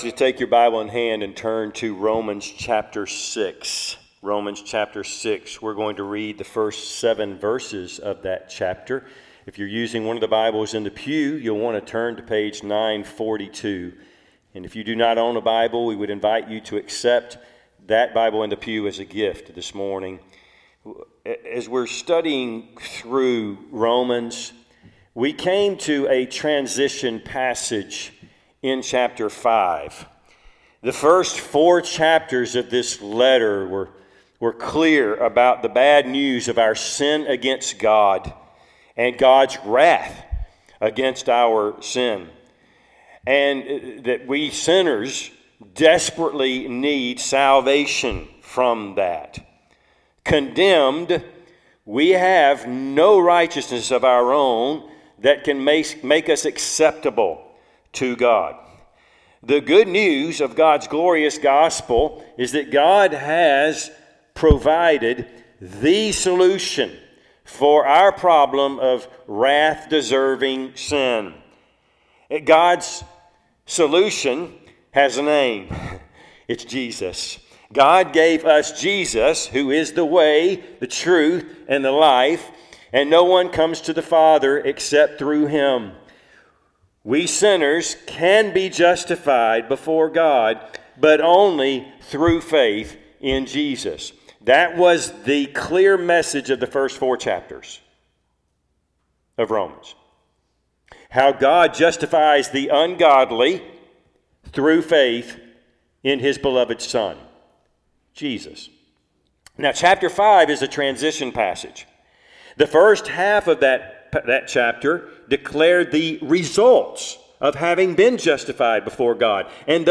Service Type: Sunday AM Topics: Christian living , justification , Sanctification